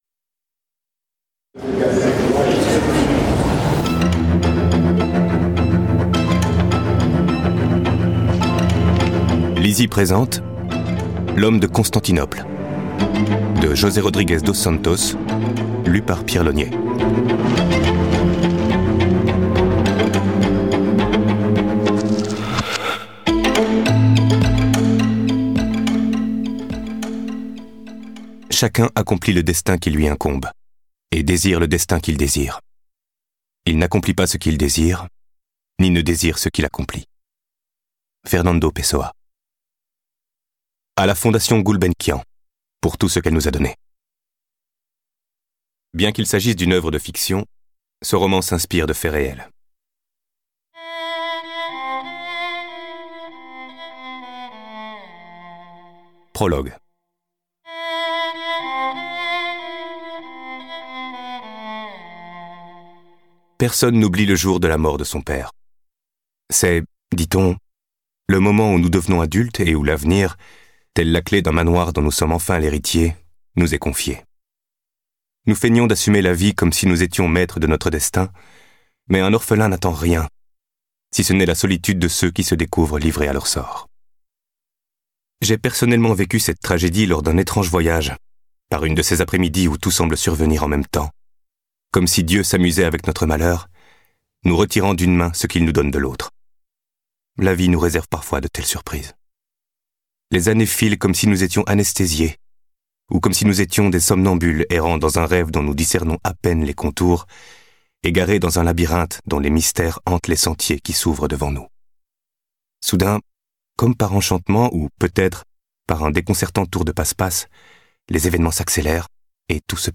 Click for an excerpt - L'Homme de Constantinople de José Rodrigues Dos Santos